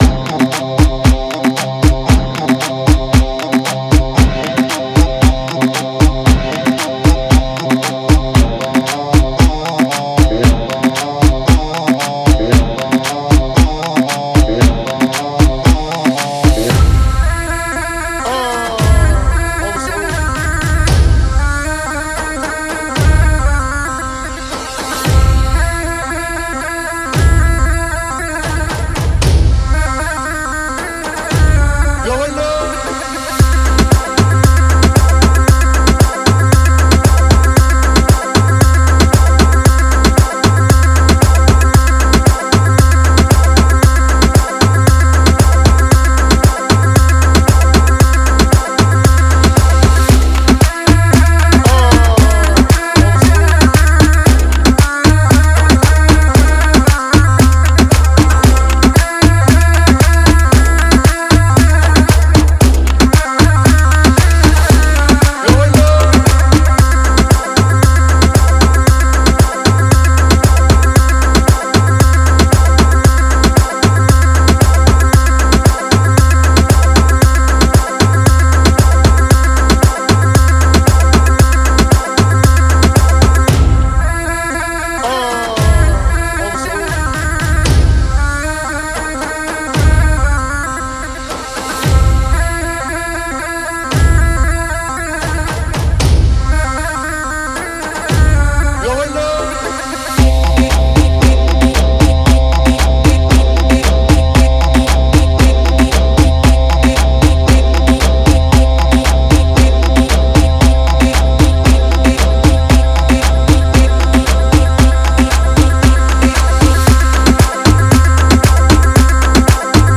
اهنگ عربی بی کلام فاز بالا خارجی